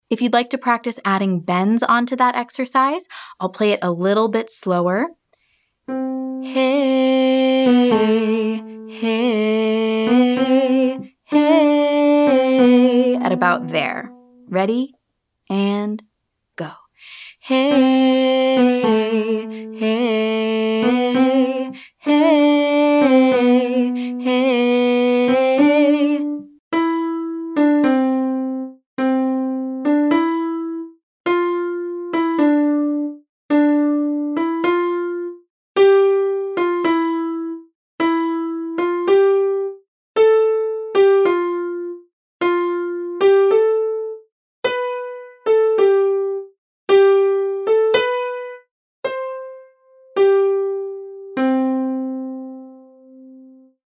And now, we’ll try it in a different key to cover a wider range.